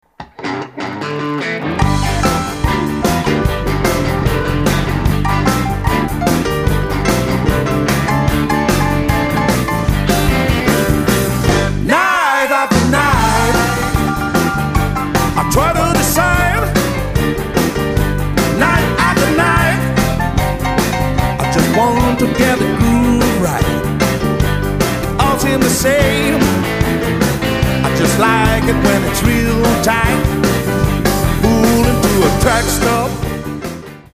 vocal, guitar
organ, piano
harp
bass
drums